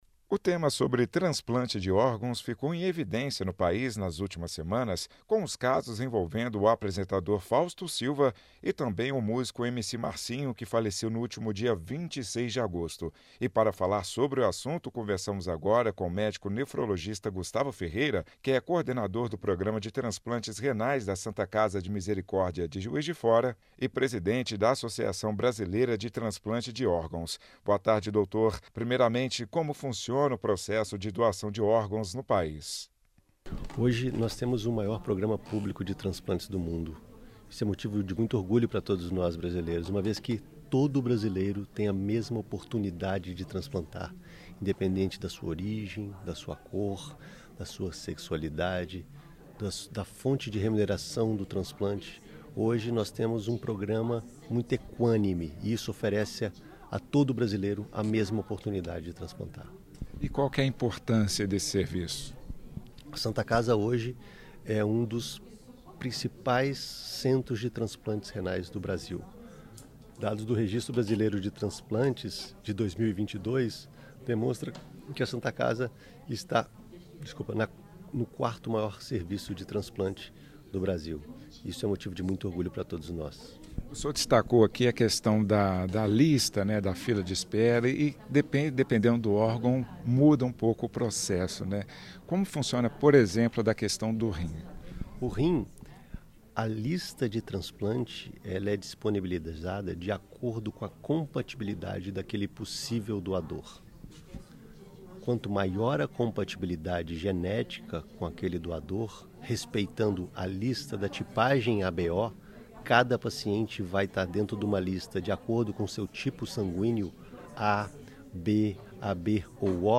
04.09_Itatiaia-Entrevista-transplante-de-orgaos.mp3